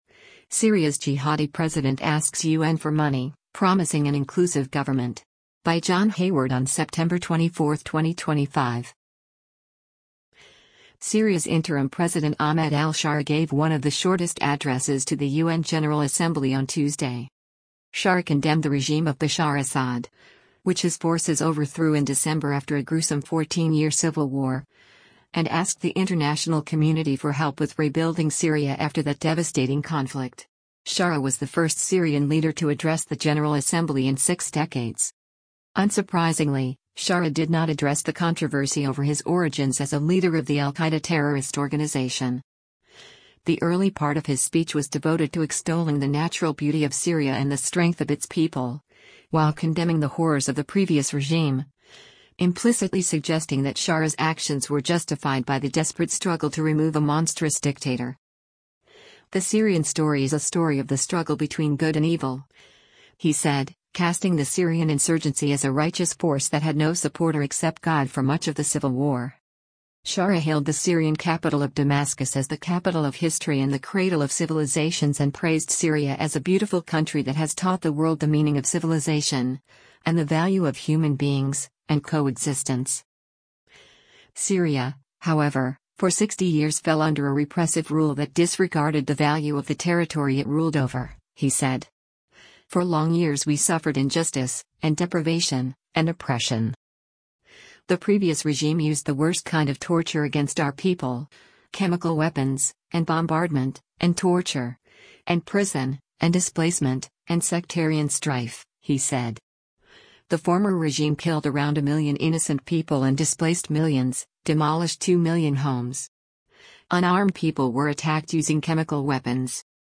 President of Syria Ahmed al-Sharaa addresses the United Nations (UN) General Assembly duri
Sharaa concluded his speech by declaring Syria “stands firmly with the people of Gaza,” a line that brought applause from parts of the General Assembly.